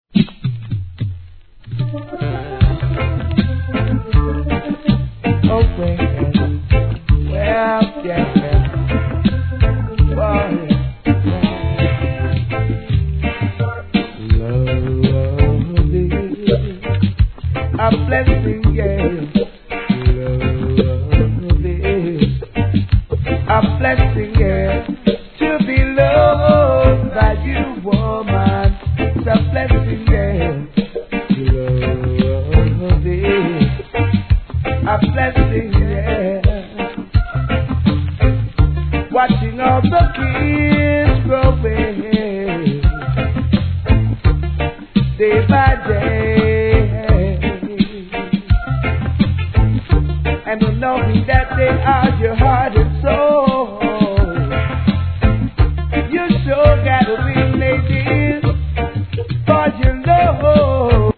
REGGAE
さすがのVOCALで聴かせます。後半inst.接続です。